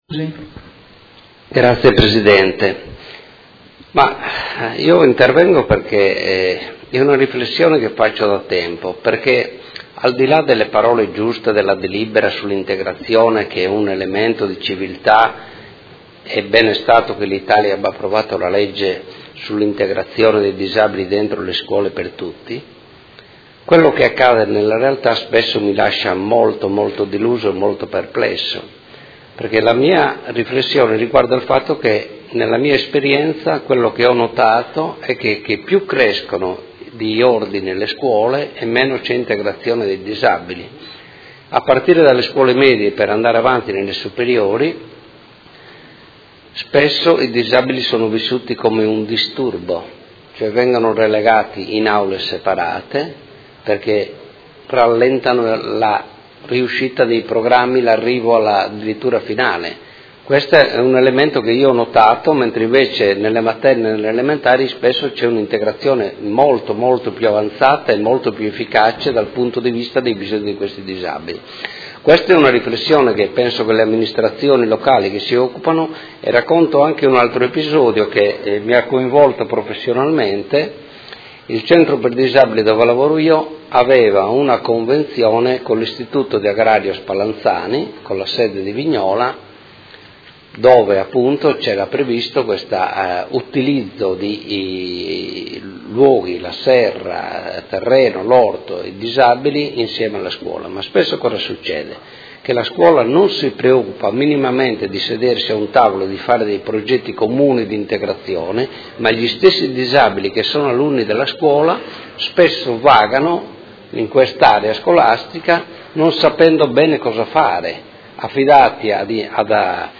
Seduta del 20/07/2017 Dibattito.